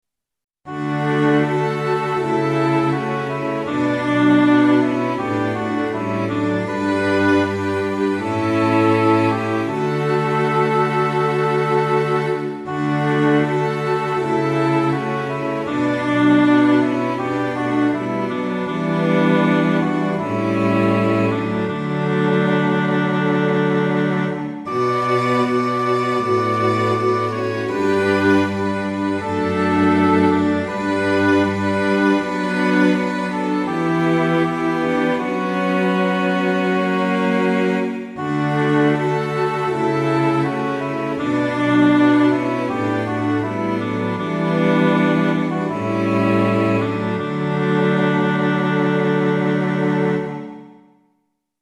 ♪聖歌隊練習補助
Tonality = D
Pitch = 440
♪   オーボエ